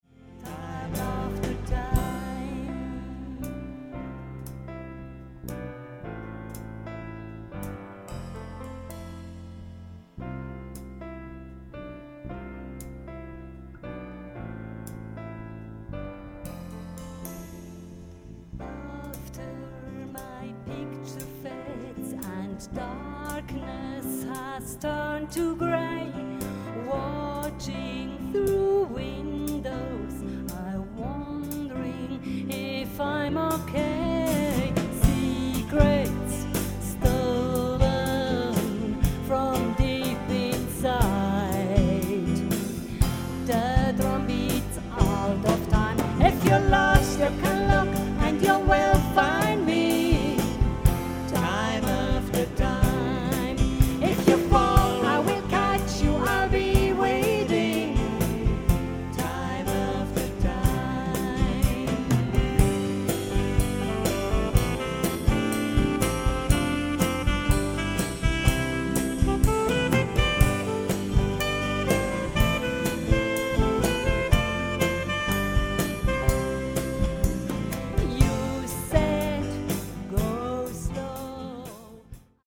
Wohlen, Sternensaal